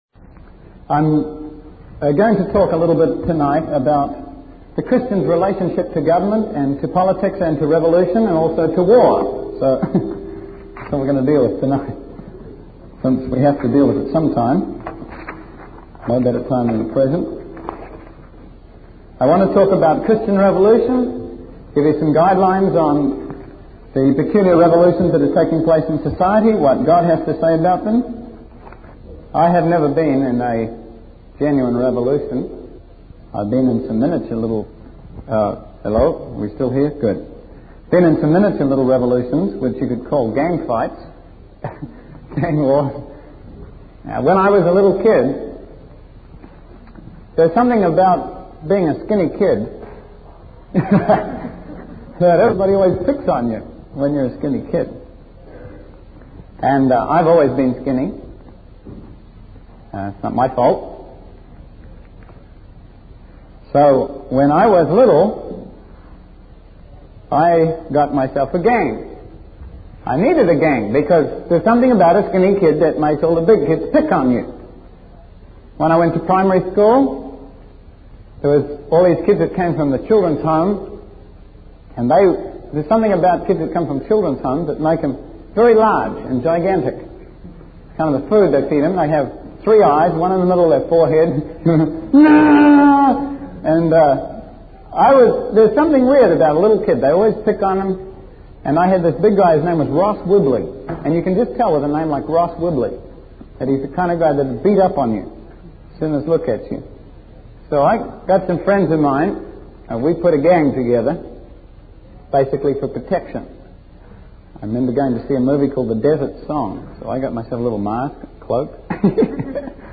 In this sermon, the preacher discusses the story of Daniel and the lions' den as an example of serving the Lord and facing challenges. He emphasizes the importance of unity in a nation, which is based on common knowledge and common unselfishness.